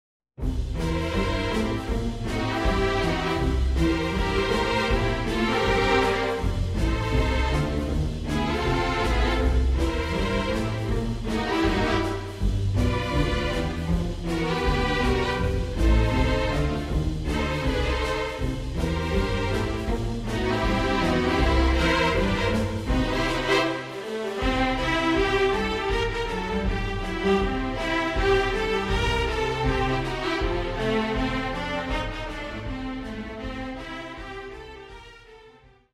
simple boogie based tunes